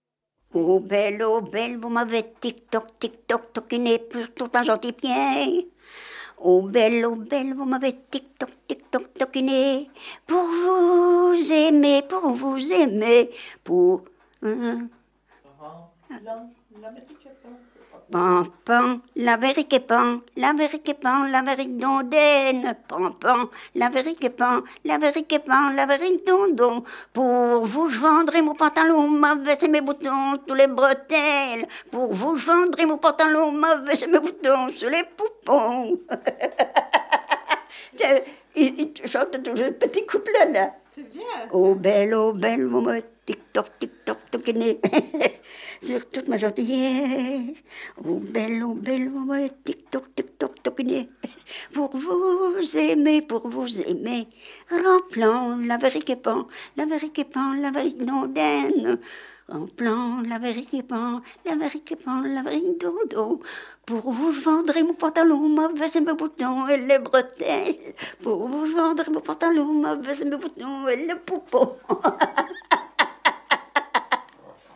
Genre : chant
Type : chanson narrative ou de divertissement
Lieu d'enregistrement : Vierves-sur-Viroin
Support : bande magnétique